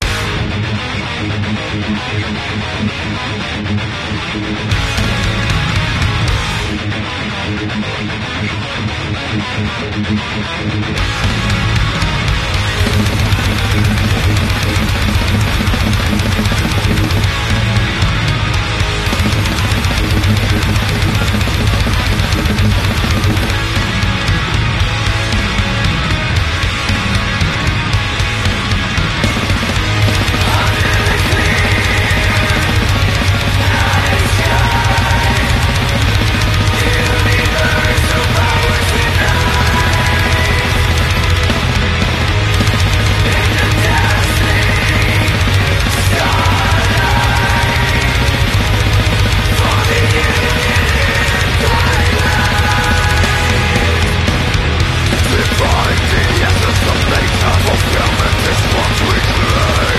Norway Genre:Symphonic black metal, melodic death metal.